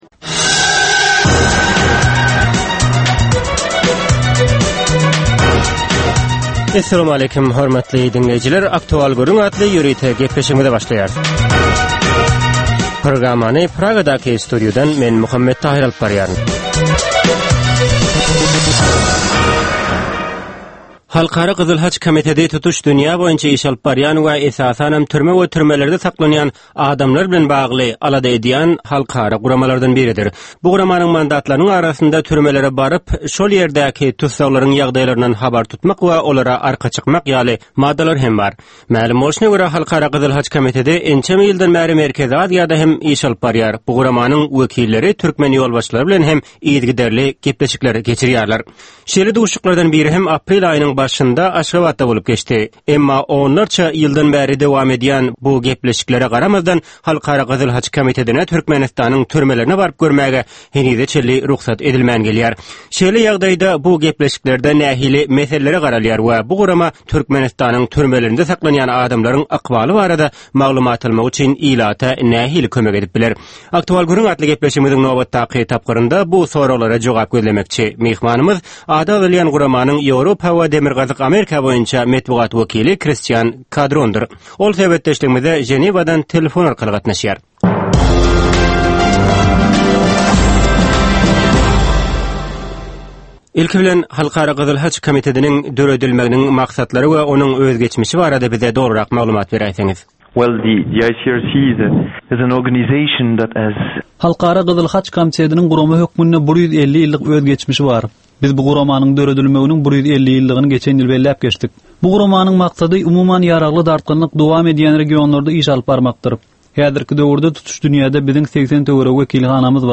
Hepdäniň dowamynda Türkmenistanda ýa-da halkara arenasynda ýüze çykan, bolup geçen möhüm wakalar, meseleler barada anyk bir bilermen ýa-da synçy bilen geçirilýän ýörite söhbetdeşlik. Bu söhbetdeşlikde anyk bir waka ýa-da mesele barada synçy ýa-da bilermen bilen aktual gürründeşlik geçirilýär we meseläniň dürli ugurlary barada pikir alyşylýar.